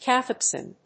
/kəˈθɛpsɪn(米国英語)/